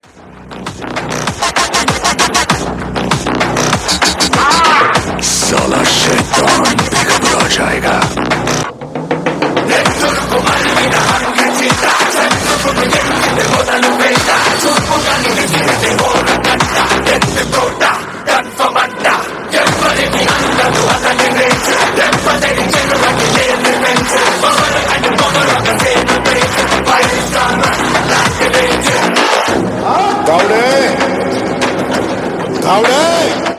loud, clear sound
best flute ringtone download